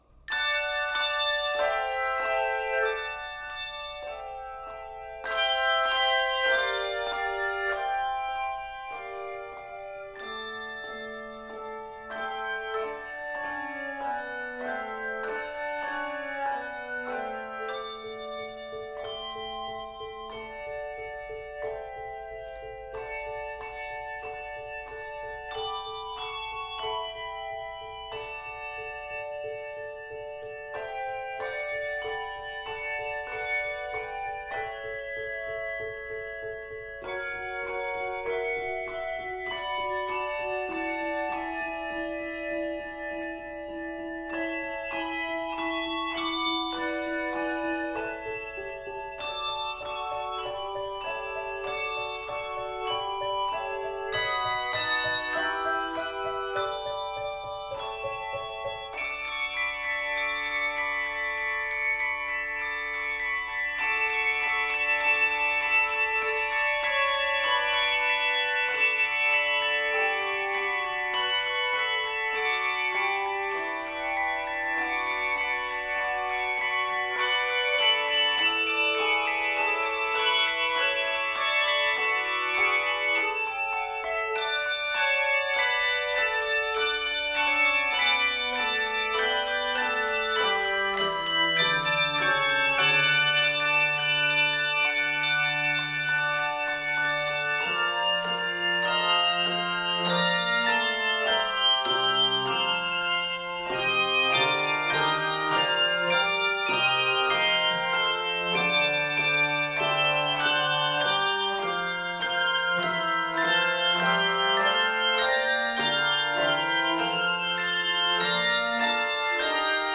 An opening chordal peal sets the tone
Octaves: 3-5